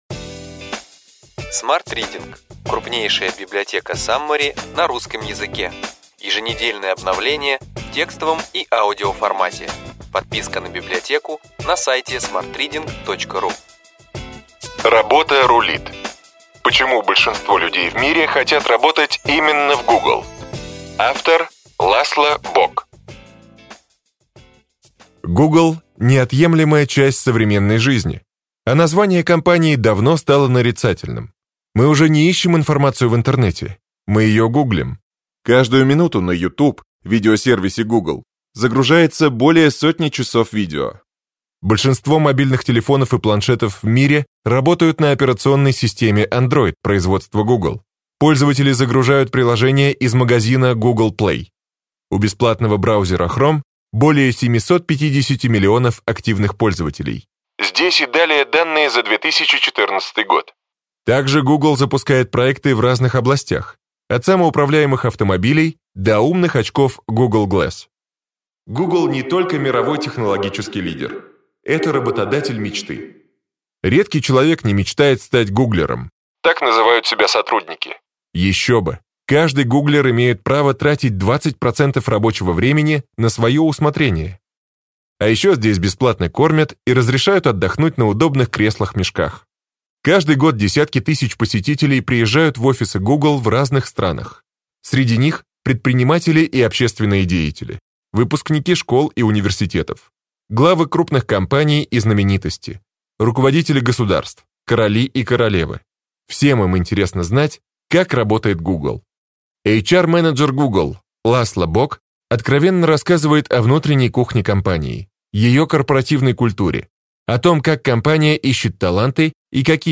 Аудиокнига Ключевые идеи книги: Работа рулит! Почему большинство людей в мире хотят работать именно в Google.